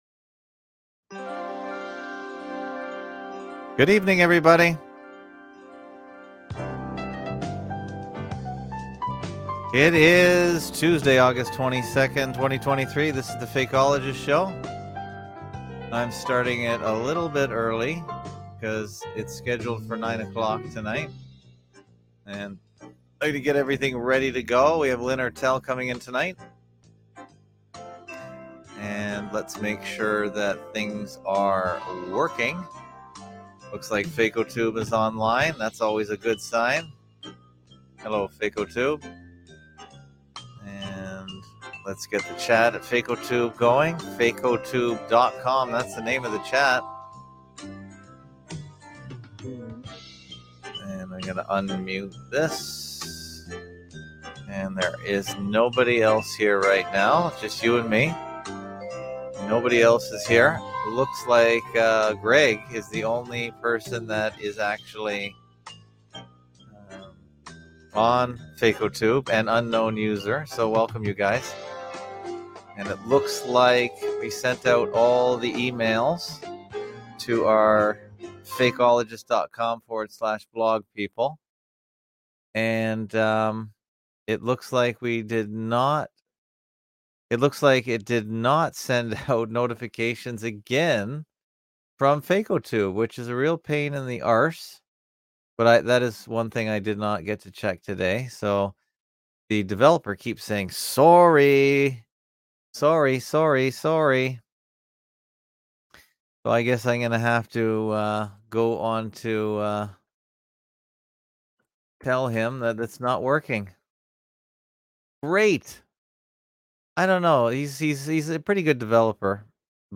Category: Live Stream